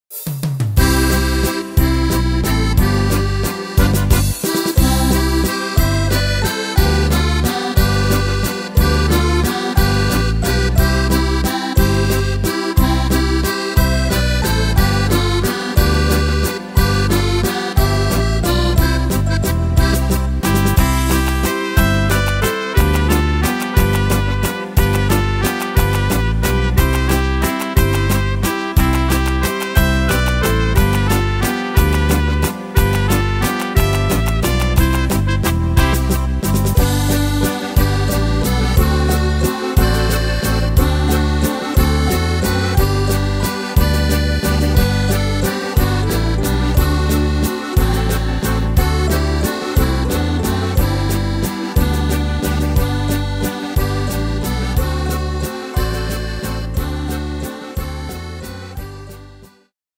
Tempo: 180 / Tonart: C-Dur